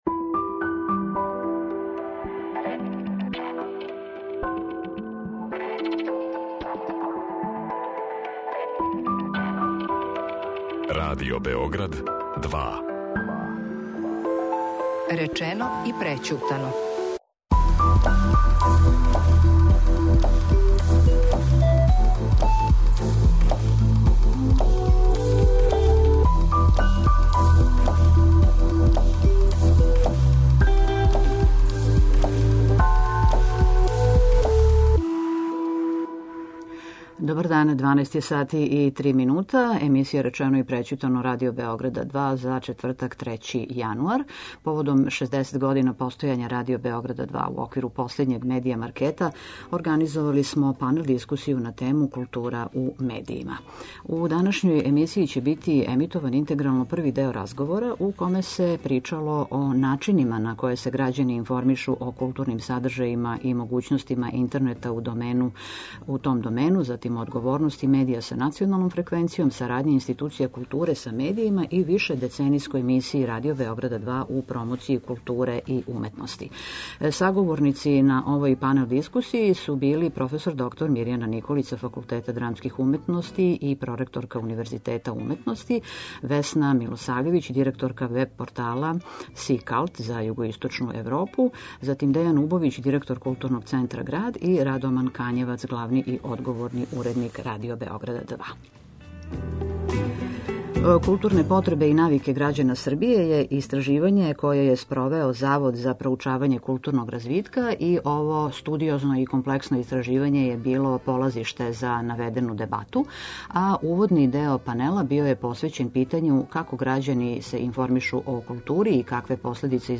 Поводом 60 година постојања Радио Београд 2 је у оквиру последњег Медија маркета, организовао панел дискусију на тему "Култура у медијима".